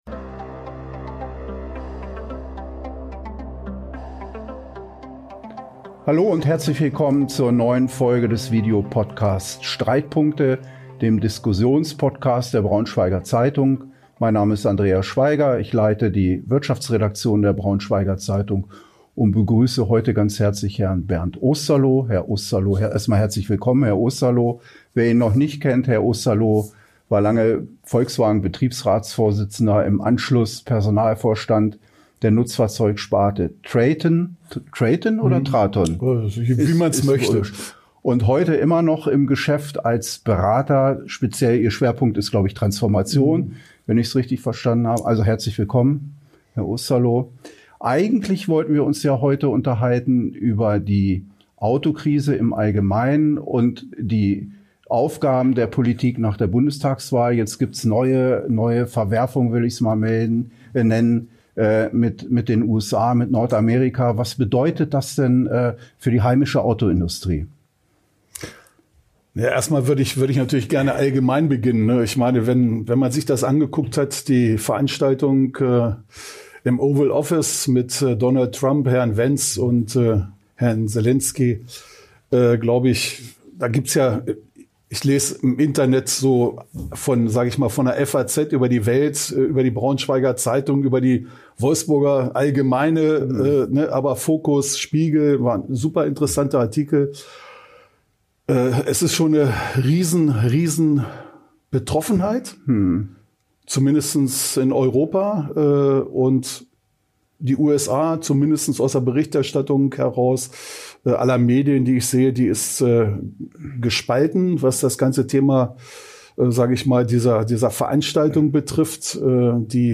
Klare Kante gegen Donald Trump? Ja, sagt Bernd Osterloh ~ Streitpunkte – der Diskussions-Podcast der Braunschweiger Zeitung Podcast